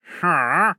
Minecraft Version Minecraft Version latest Latest Release | Latest Snapshot latest / assets / minecraft / sounds / mob / wandering_trader / no2.ogg Compare With Compare With Latest Release | Latest Snapshot